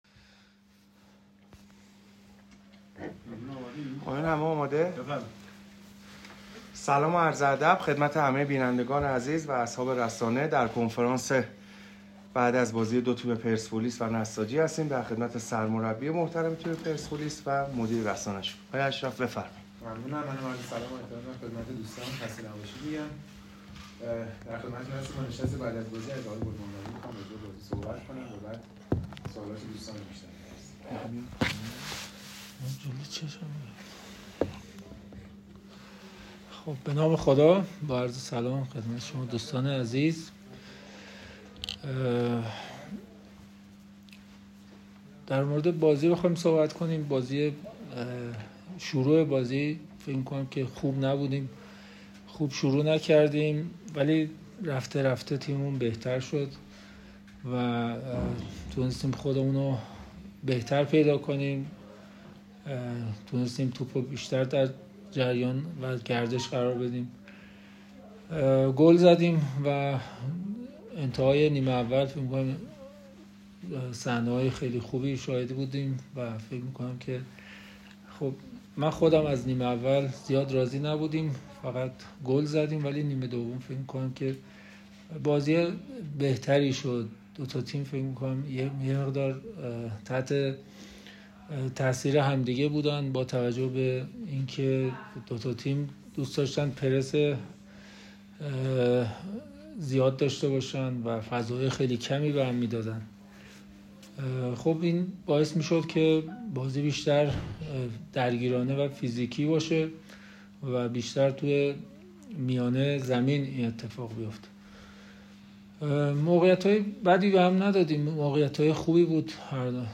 کنفرانس خبری گل محمدی و الهامی
کنفرانس مطبوعاتی سرمربی تیم‌های پرسپولیس و نساجی قائمشهر پس از دیدار دو تیم در چارچوب هفته دوم رقابت‌های لیگ برتر برگزار شد.
یحیی گل محمدی- سرمربی پرسپولیس